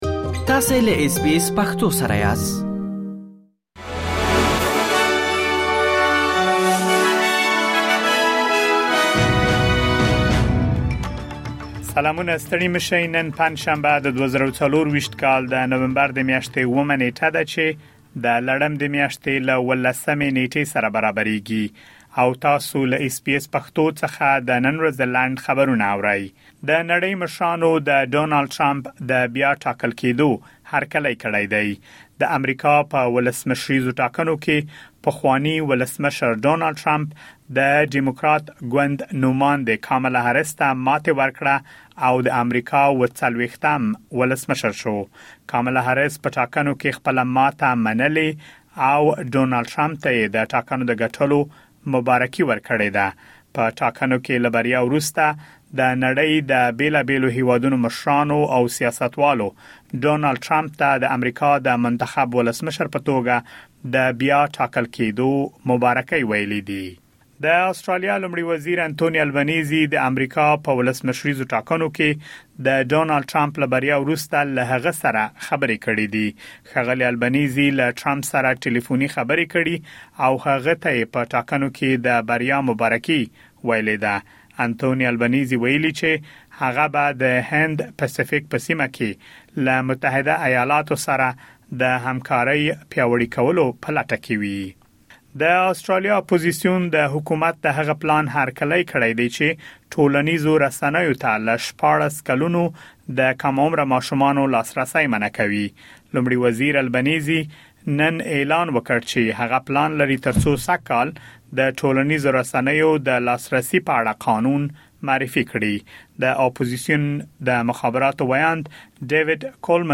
د اس بي اس پښتو د نن ورځې لنډ خبرونه |۷ نومبر ۲۰۲۴